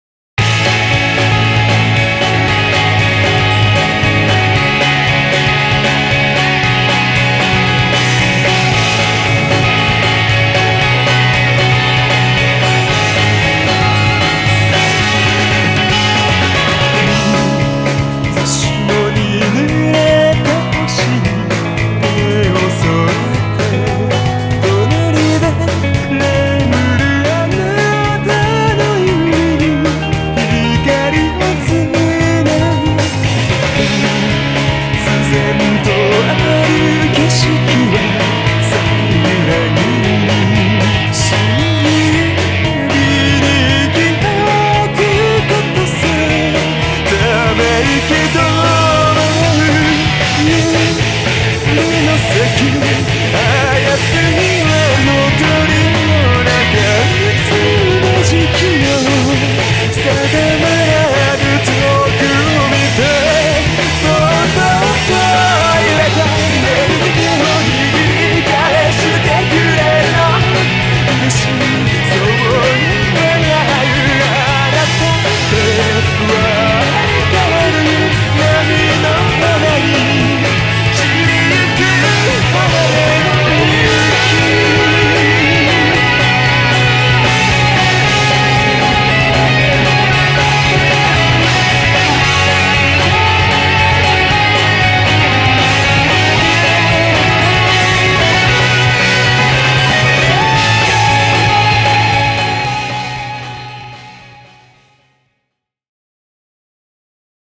BPM230
Audio QualityPerfect (Low Quality)